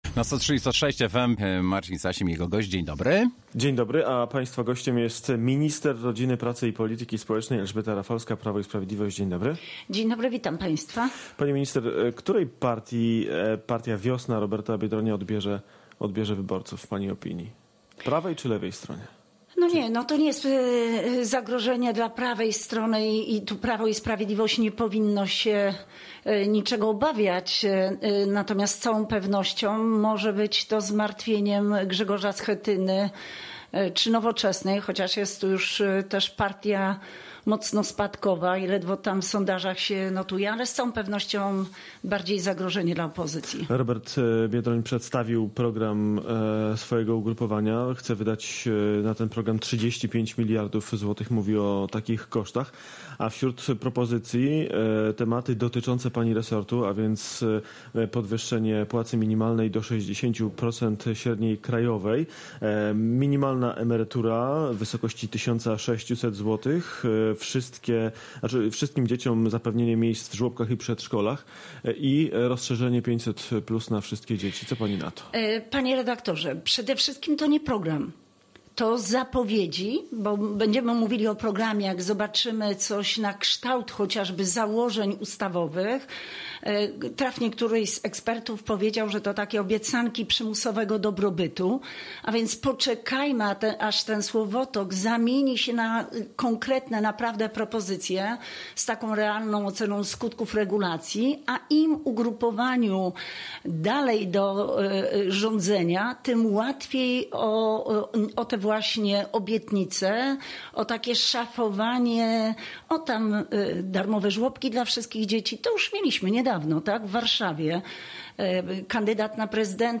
Z minister rodziny, pracy i polityki społecznej rozmawia